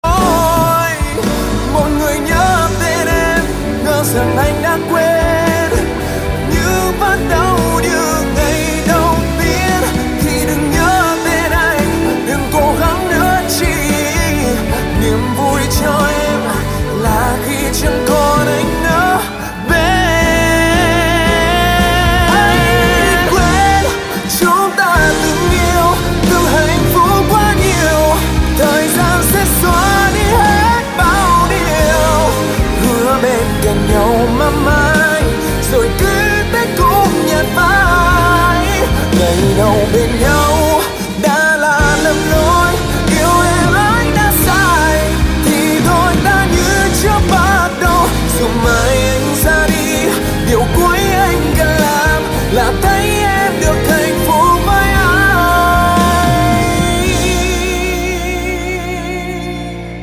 Thể loại nhạc chuông: Nhạc trẻ HOT